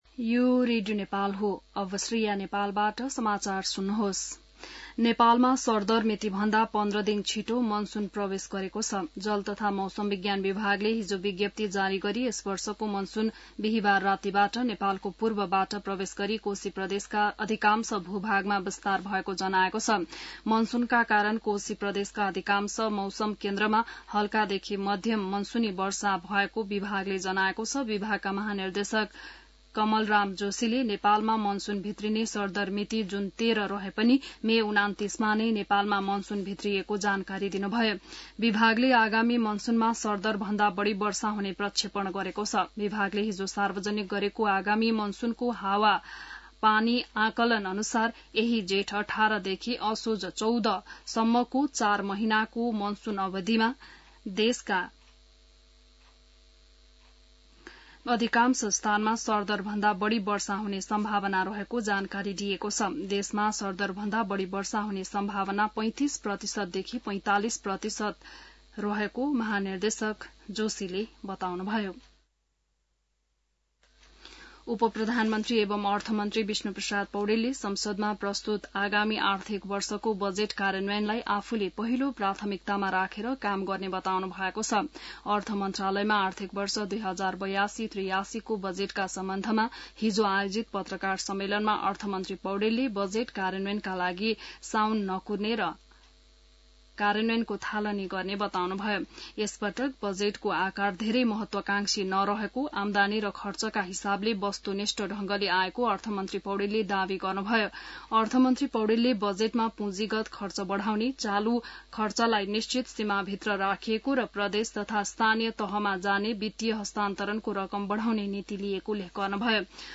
बिहान ६ बजेको नेपाली समाचार : १७ जेठ , २०८२